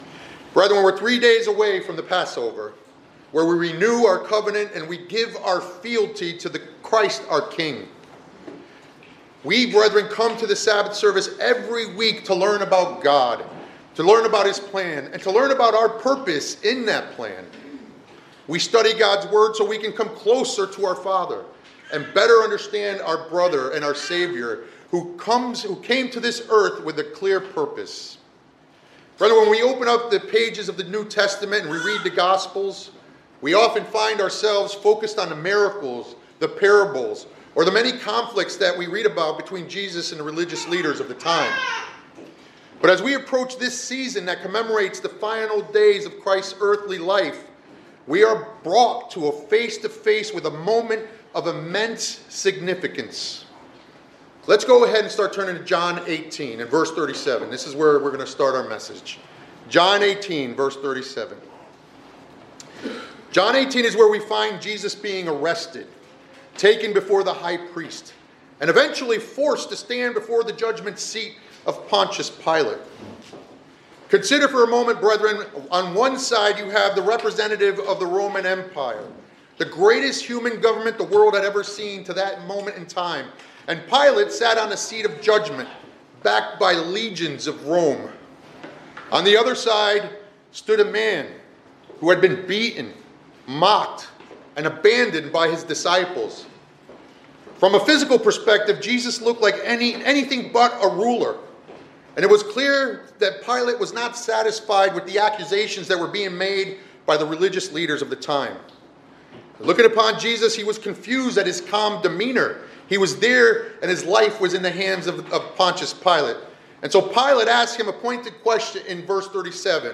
This sermonette explains that Jesus Christ was born to be King, not merely to teach or inspire, but to restore God’s government to a world that rejected it, as shown in His confrontation with Pilate in John 18.
Given in Hartford, CT